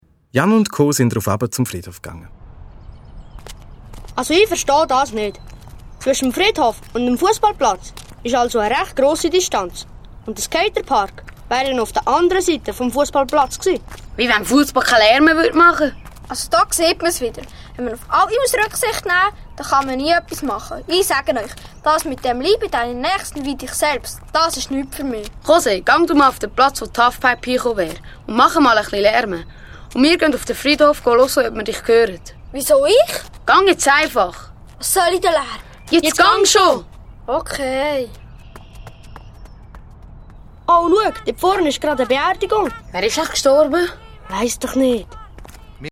Detektivserie 2. Fall
Hörspiel-CD mit Download-Code